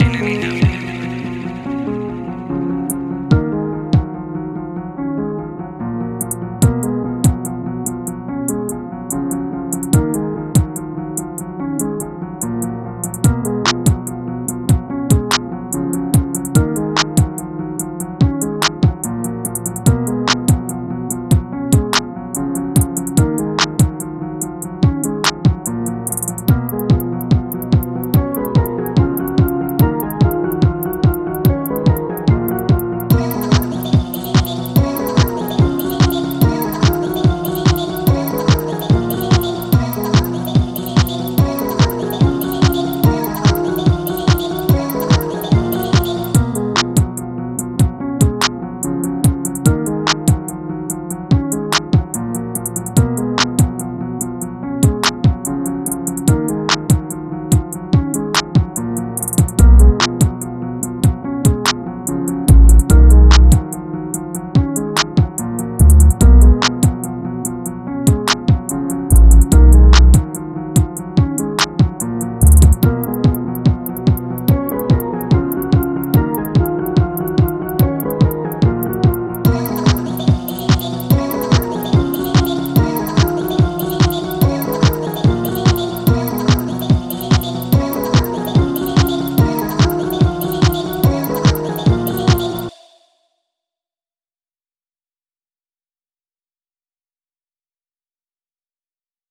Time – (1:39)　bpm.145